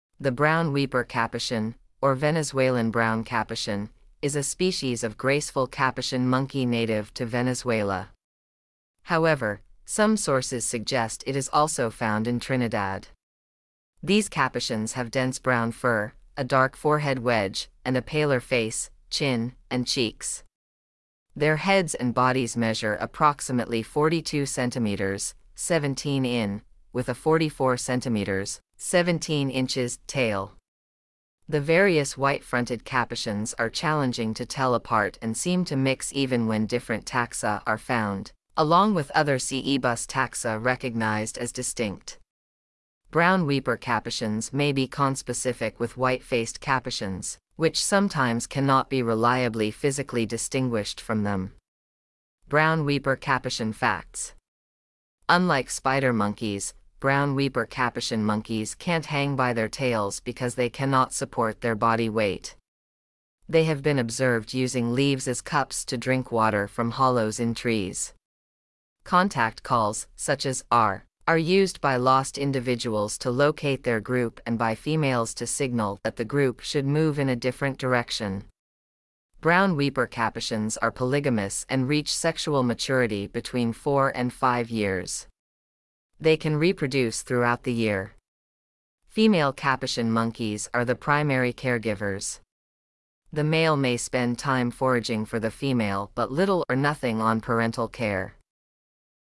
Brown Weeper Capuchin
• Contact calls (such as “arrh“) are used by lost individuals to locate their group and by females to signal that the group should move in a different direction.
brown-weeper-capuchin.mp3